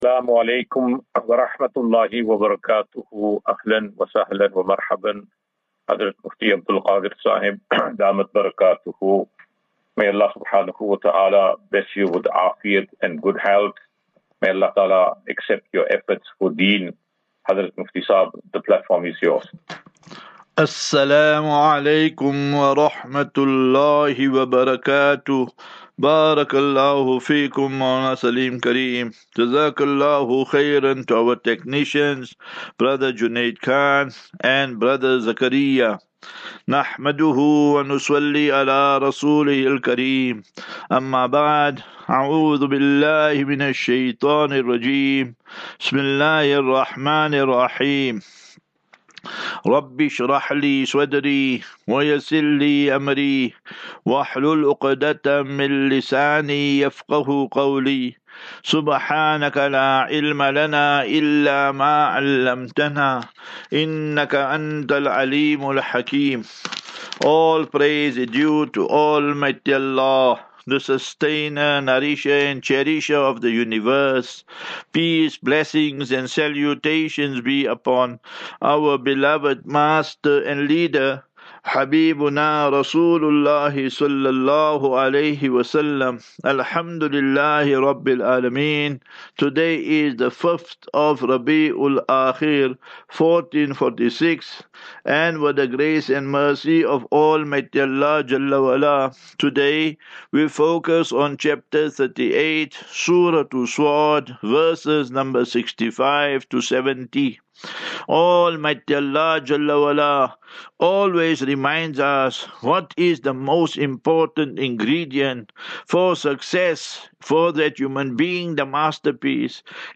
9 Oct 09 October 2024. Assafinatu - Illal - Jannah. QnA